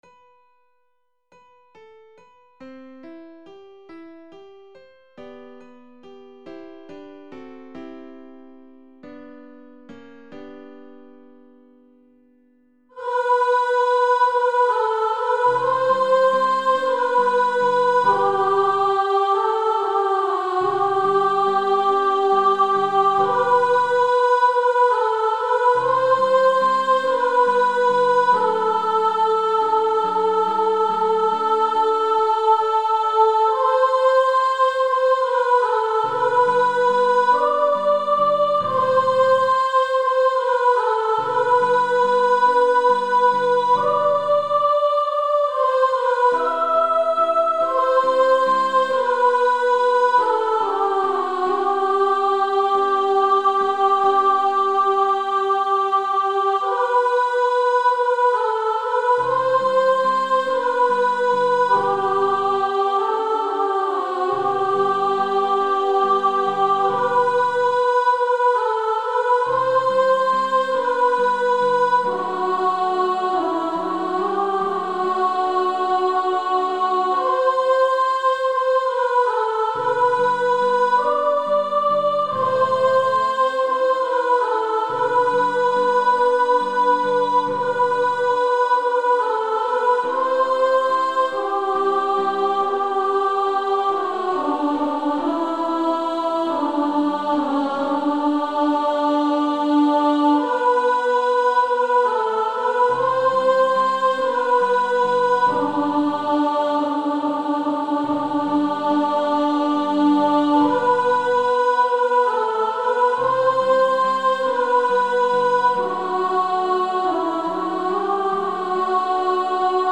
Practice then with the Chord quietly in the background.